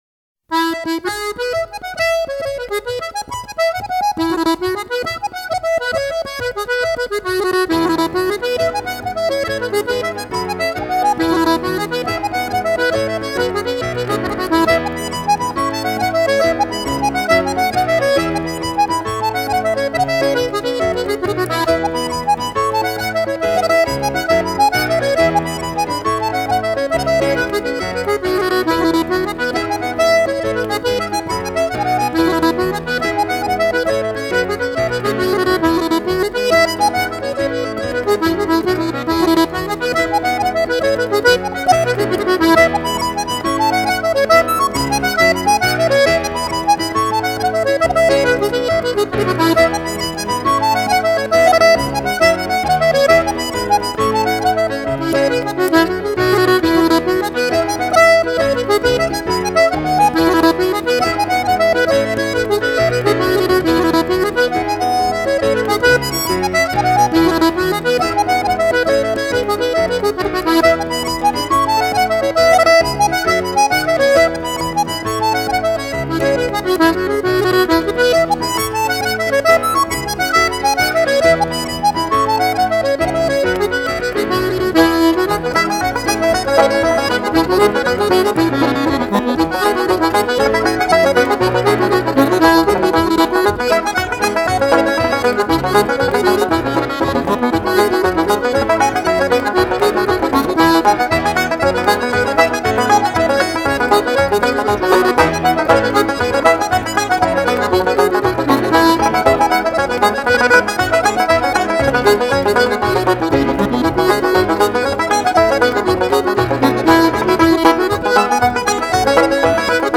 刚才曾提到jig舞 这就是一支典型的jig舞曲 从平缓到越来越欢快 仿佛在参加一场爱尔兰乡间丰收后的party